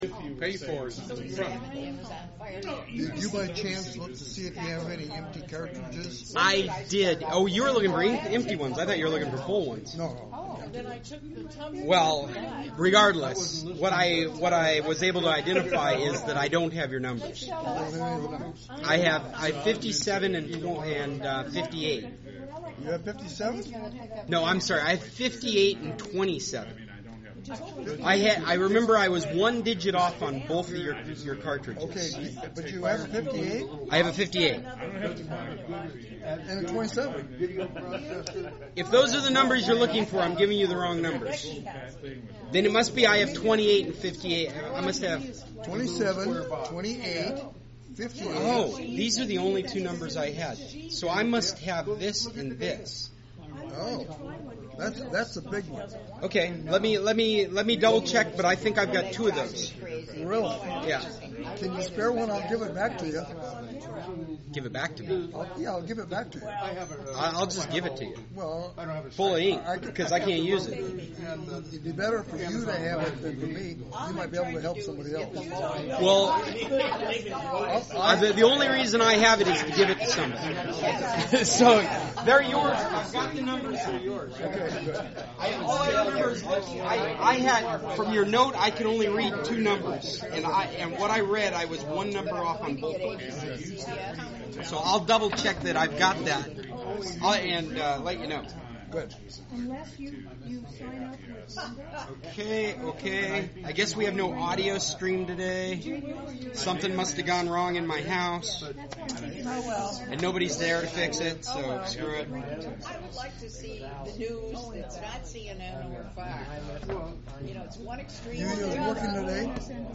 A lot of fun conversation, and we got to use the big screen a little bit.